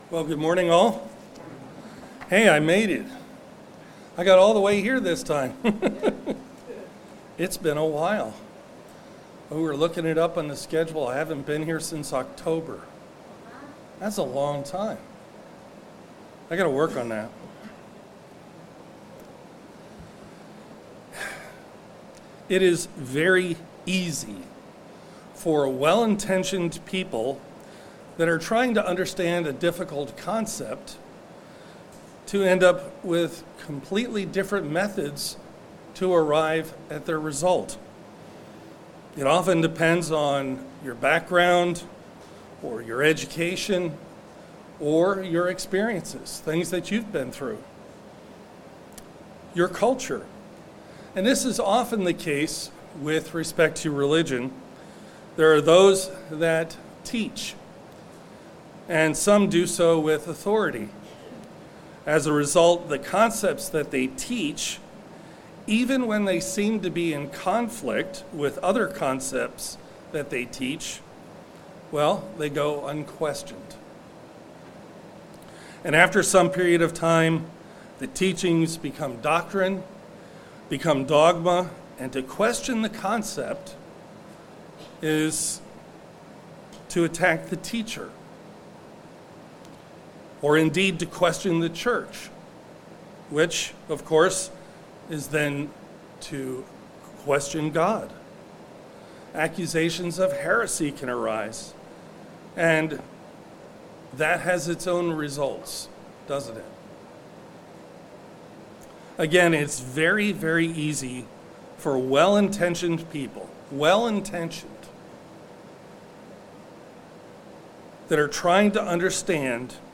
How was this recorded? Given in Delmarva, DE